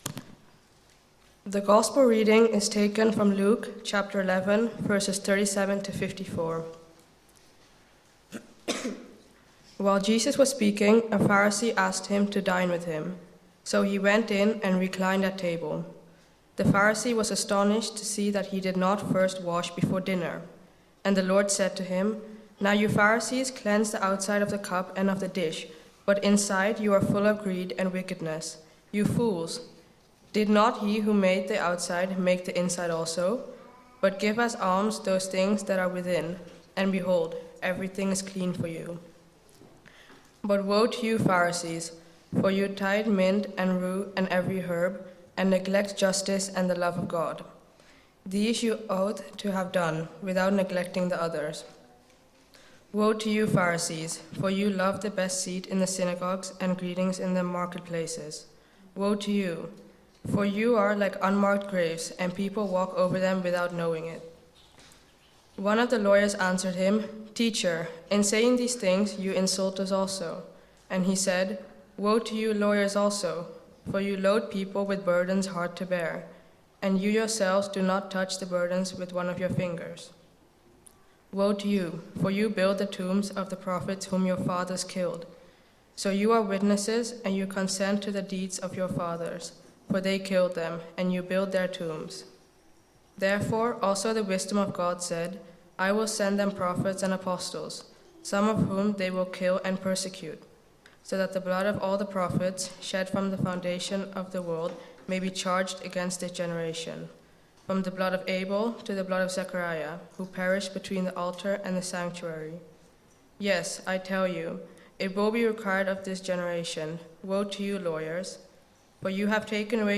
Sermon Have you heard about fake doctors, fake architects , fake engineers?